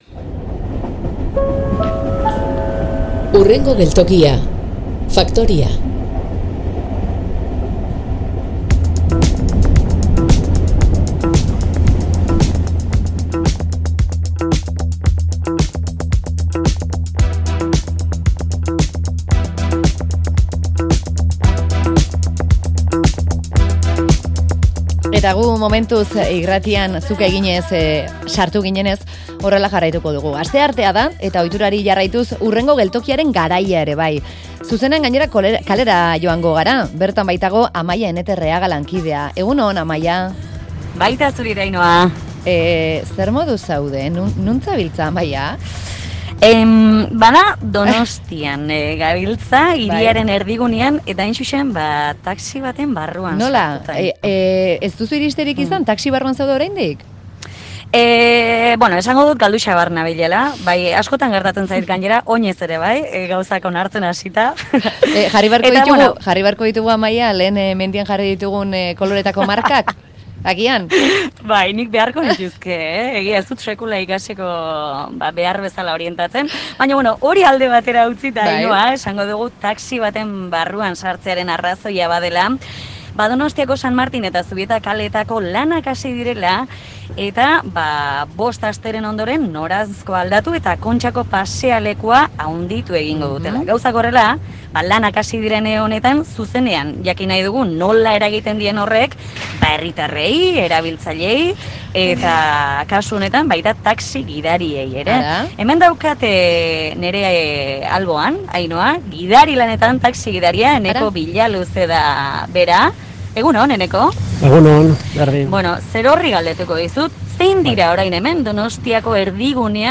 Audioa: Donostiako San Martin eta Zubieta kaleen artean egon diren aldaketak bertatik ezagutzeko, taxi gidariarekin osatu dugu Hurrengo geltokia.